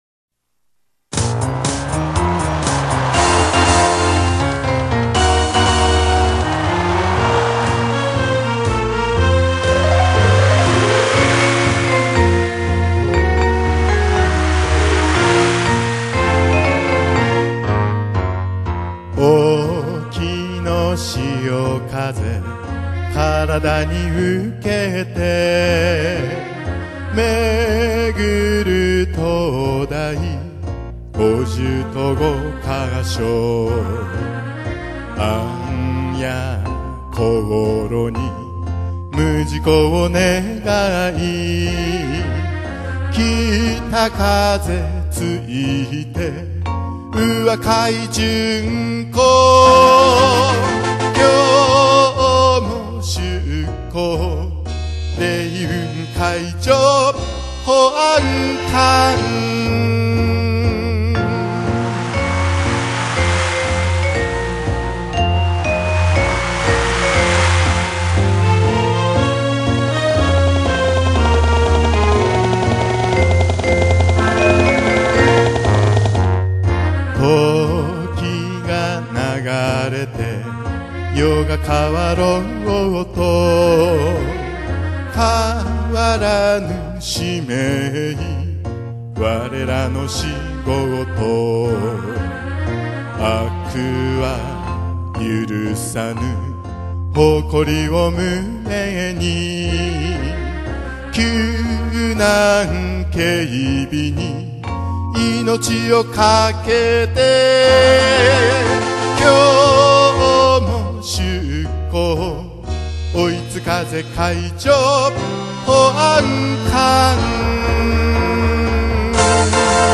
海保への応援歌